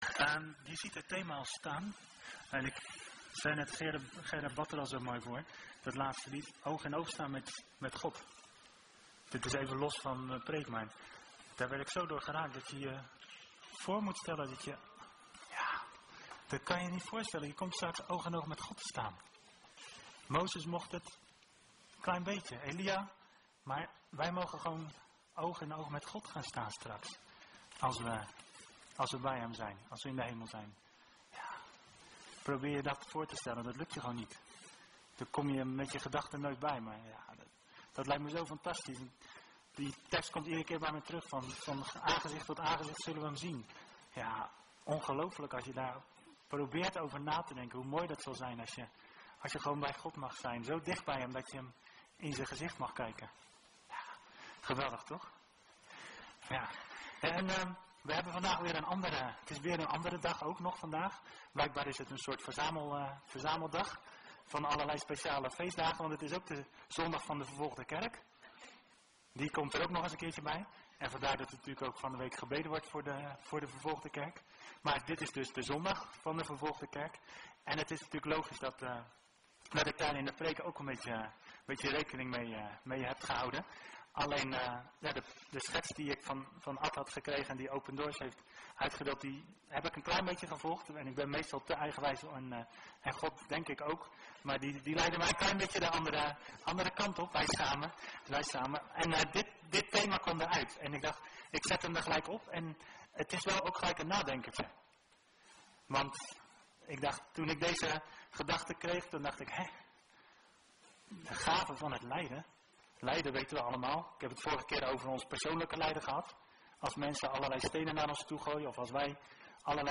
Video opname preek 28 oktober 2018